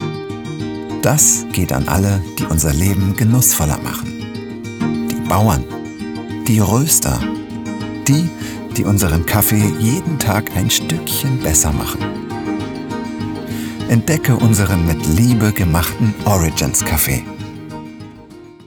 dunkel, sonor, souverän, plakativ
Mittel plus (35-65)
Werbung 01 - natürlich und weich
Commercial (Werbung)